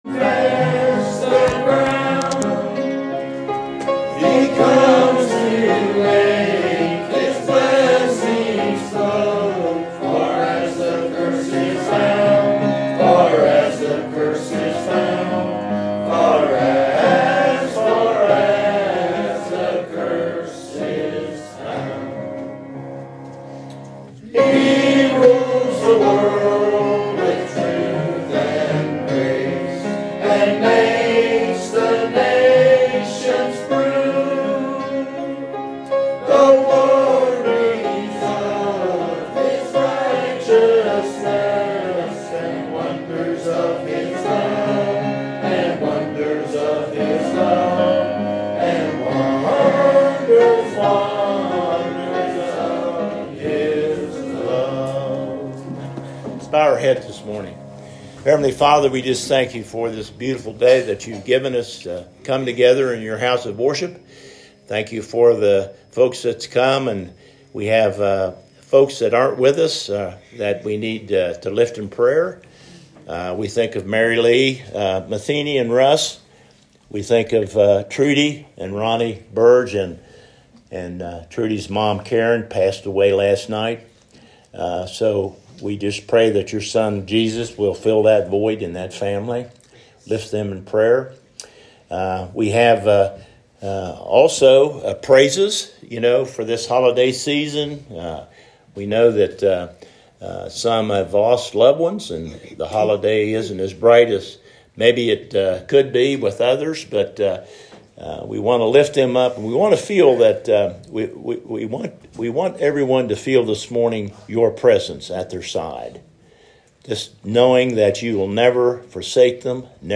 Holiday Service 2024